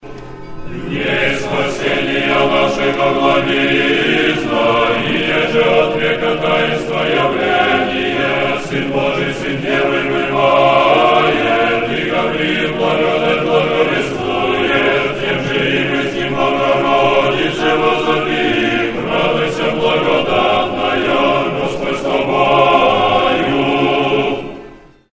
Тропарь Благовещения Пресвятой Богородицы